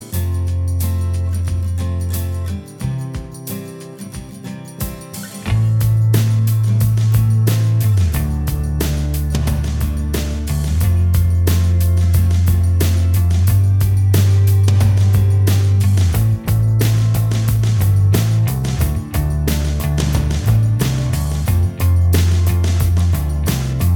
Minus Lead Guitar Indie / Alternative 4:33 Buy £1.50